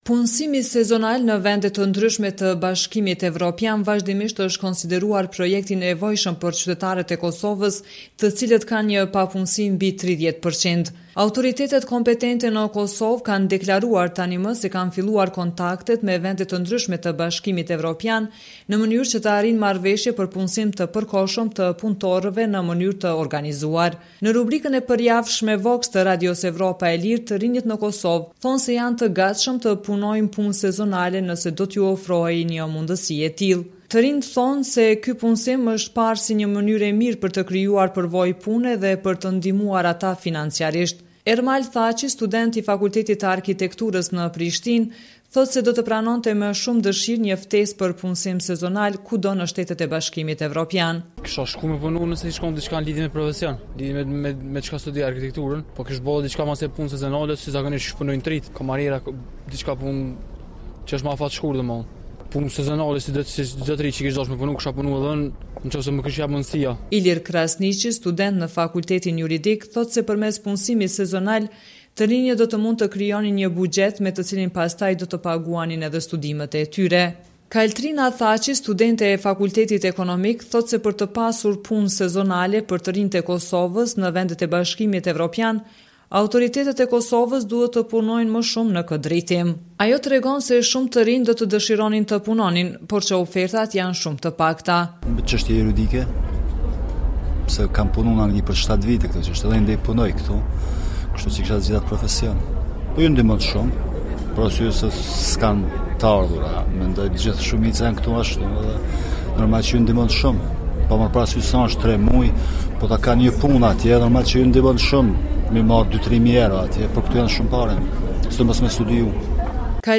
Për shumë për të rinj që janë intervistuar nga Radio Evropa e Lirë, ky punësim është parë si një mënyrë e mirë për të krijuar përvojë pune dhe për t’i ndihmuar ata financiarisht.